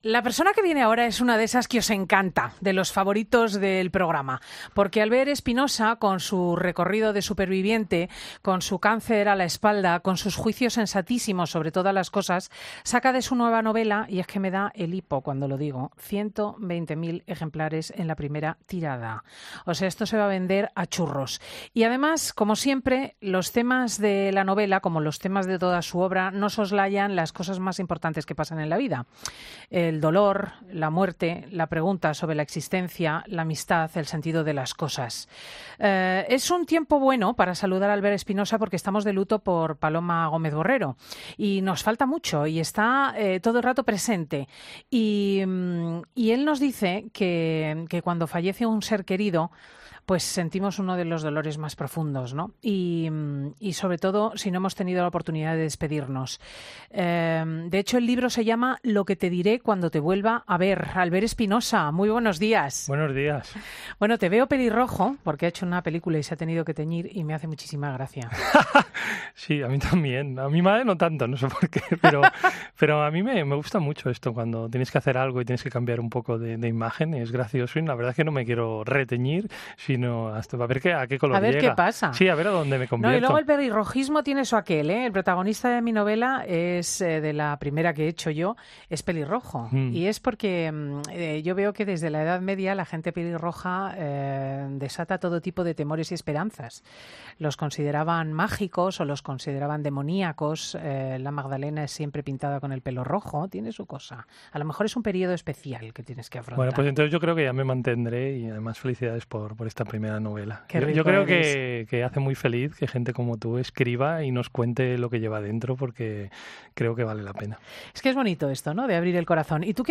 Escucha la entrevista al escritor Albert Espinosa en 'Fin de Semana'